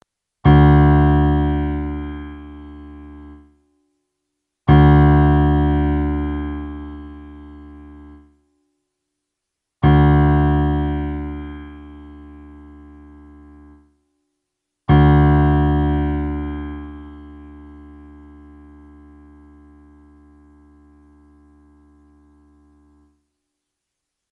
Referenzbeispiele zum Stimmen der E-, A-, D-, G- Kontrabasssaite
Wenn Sie auf die folgenden Links klicken, hören Sie, wie die Saiten klingen und können ihren Double Bass danach online stimmen:
D-Saite (mp3):
kontrabass_d.mp3